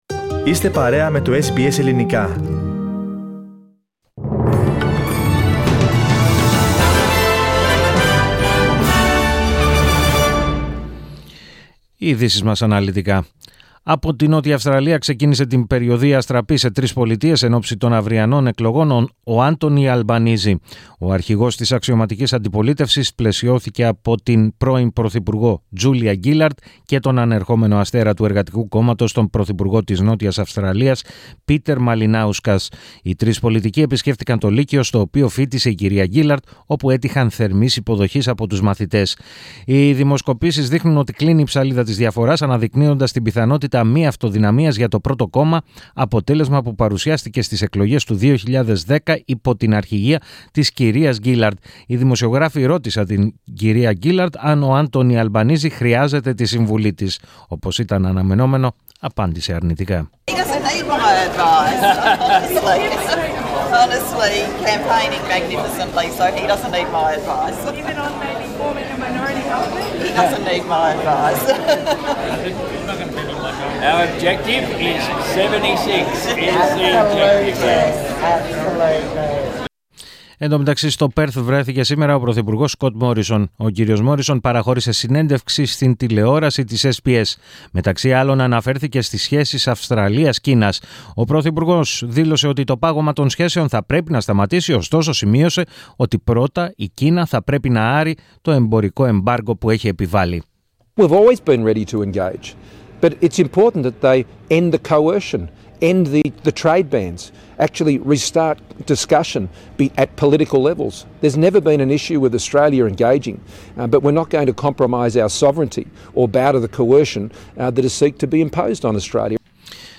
Ειδήσεις 20.05.22
Το αναλυτικό δελτίο στις 16:00